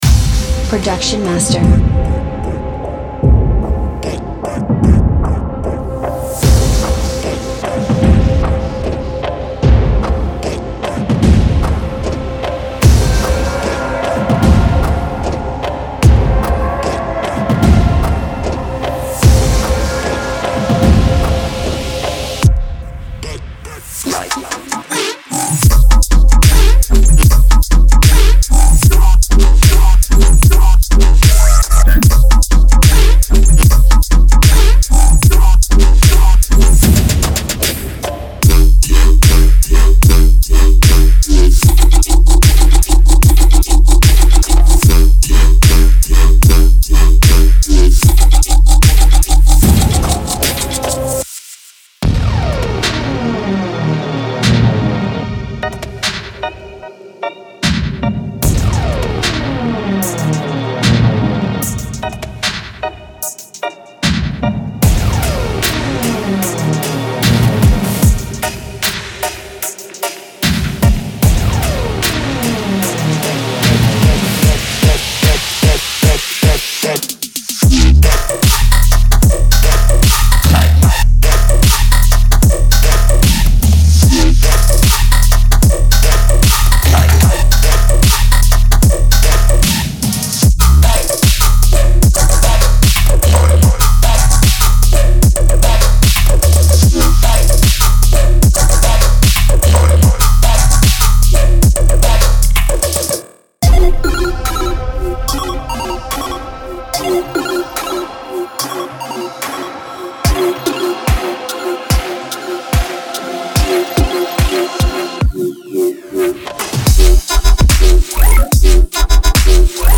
ound鼓：借助这些敲门声，锋利的帽子，巨大的军鼓和大量严重邪恶的电影鼓使您的鼓脱颖而出。